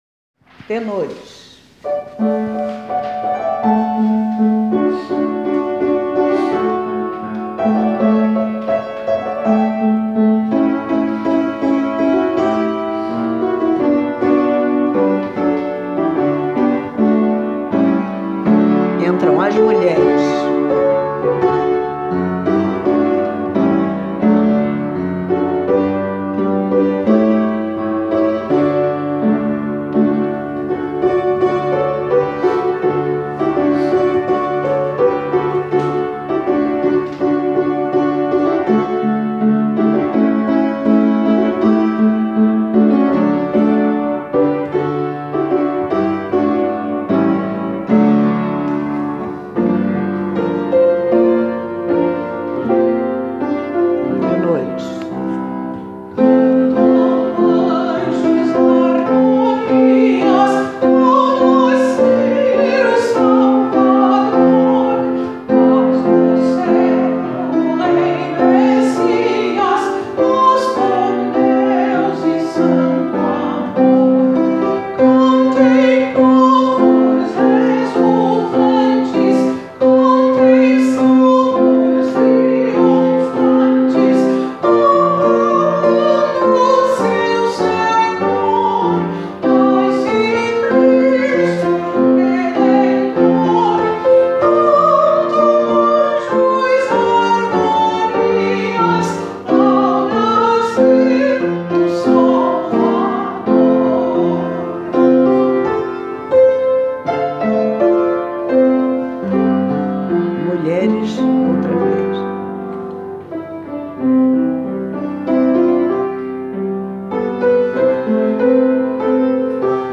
Gathering_Encontro-Tenores.mp3